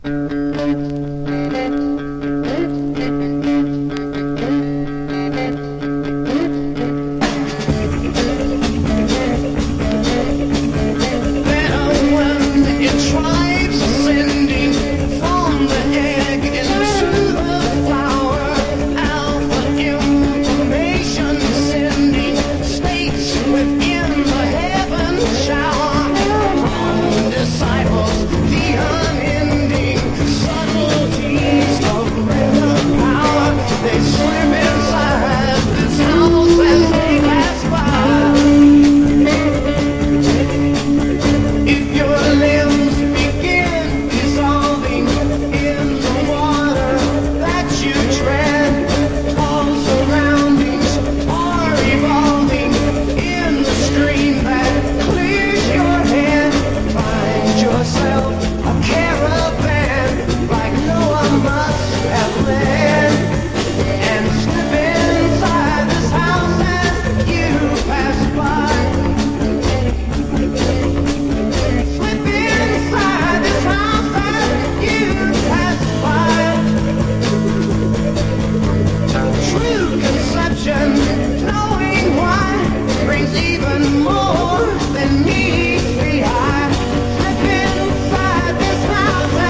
1. 60'S ROCK >